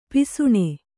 ♪ pisuṇe